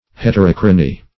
Search Result for " heterochrony" : The Collaborative International Dictionary of English v.0.48: Heterochronism \Het`er*och"ro*nism\, Heterochrony \Het`er*och"ro*ny\, n. [Gr.